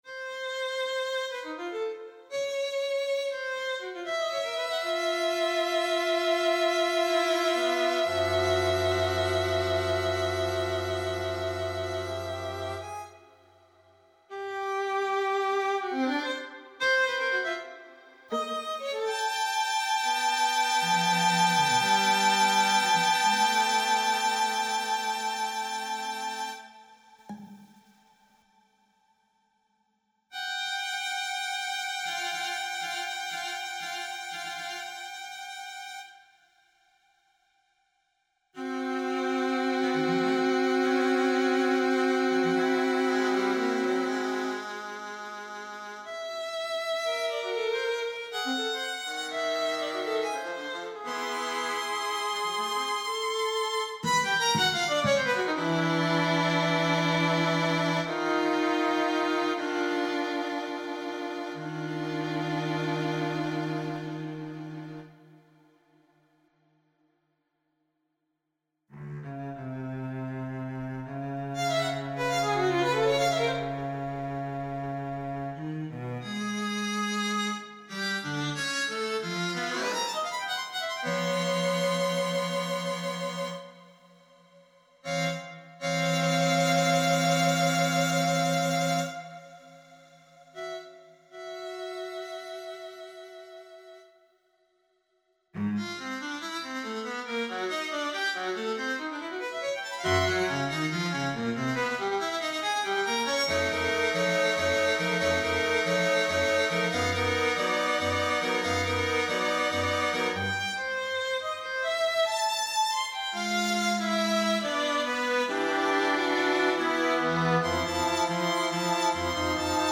Snowball's Chance Score and Electronic Performance Here's the score to the string quartet "Snowball's Chance" (a piece inspired by global warming). Here's a realization of the quartet using (cheap) samples.